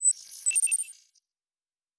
pgs/Assets/Audio/Sci-Fi Sounds/Electric/Data Calculating 2_3.wav at master
Data Calculating 2_3.wav